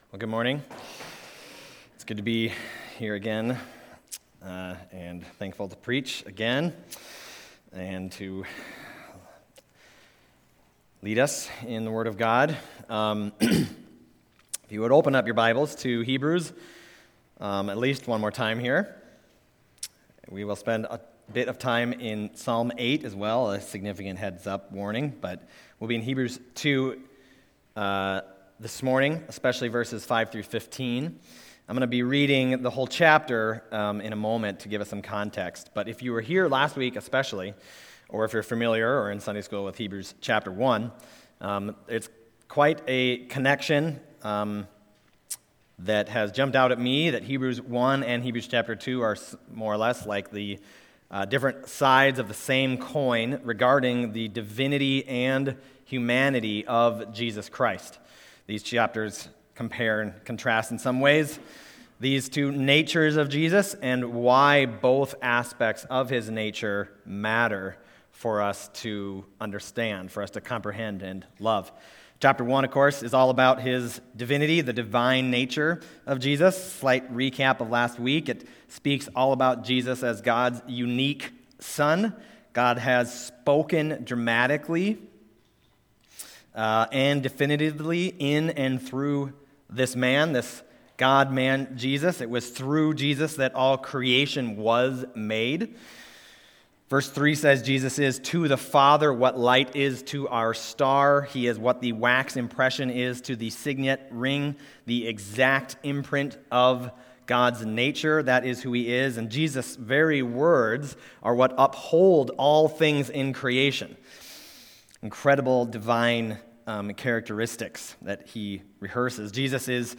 Other Passage: Hebrews 2:5-13 Service Type: Sunday Morning Hebrews 2:5-13 « Jesus